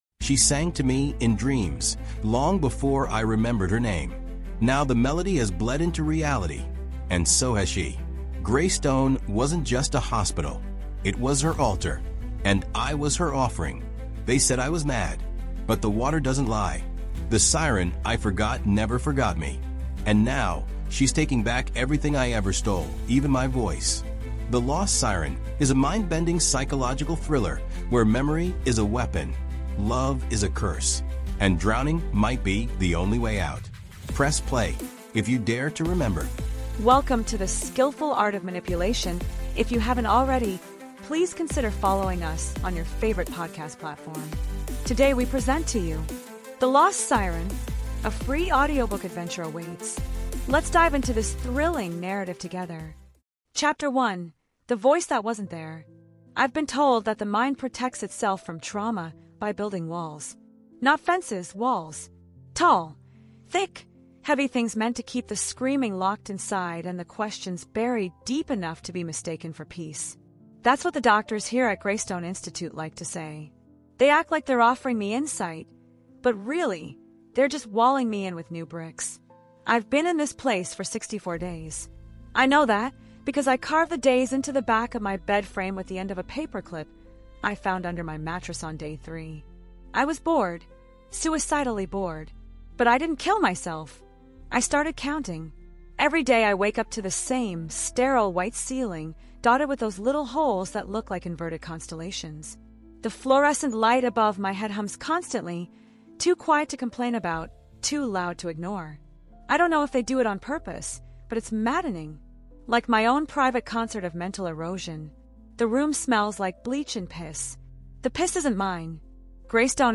The Lost Siren: A Free Audiobook Adventure Awaits | Audiobook